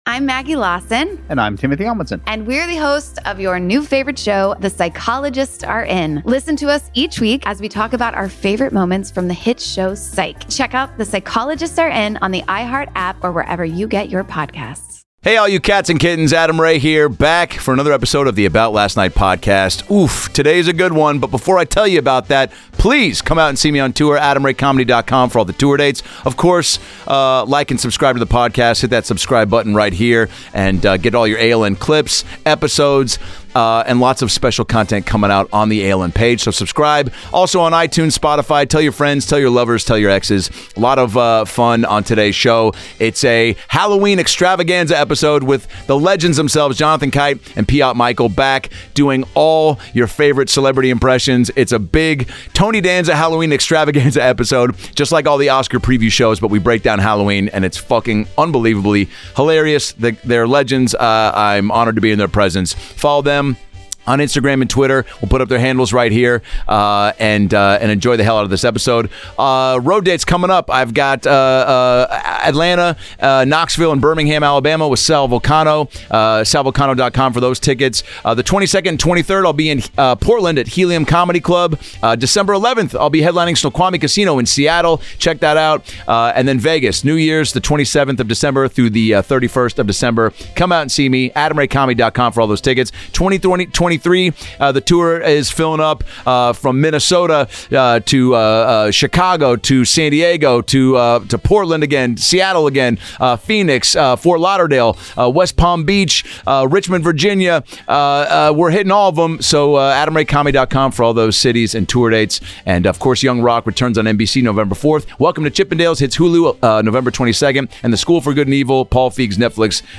for the most hilarious and fucked up, fully improvised, hour of your life!